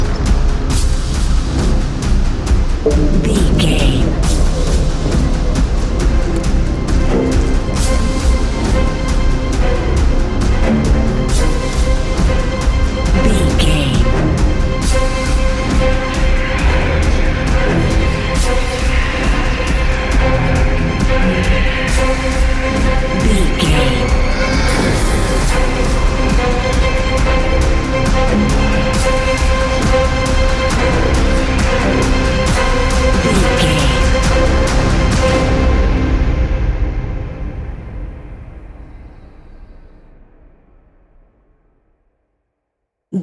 Fast paced
In-crescendo
Thriller
Ionian/Major
dark ambient
synths